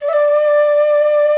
D3FLUTE83#03.wav